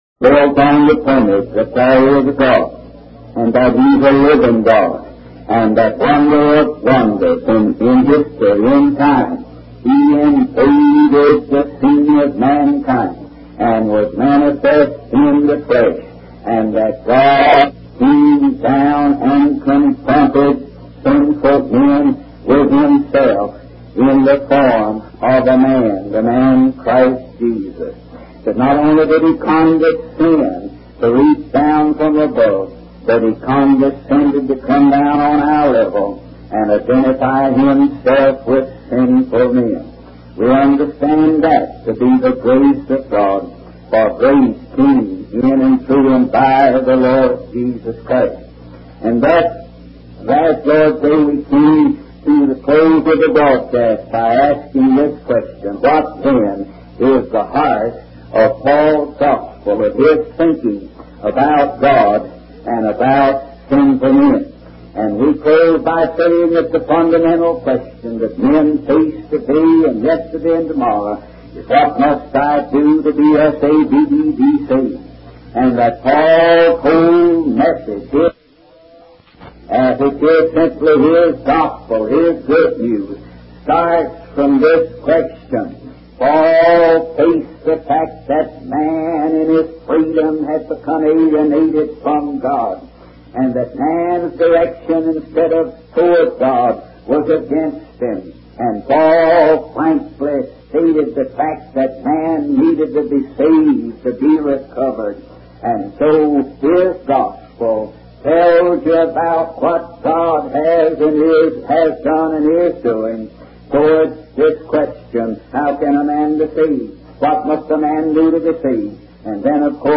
In this sermon, the preacher emphasizes the need for redemption and justification for sinful men.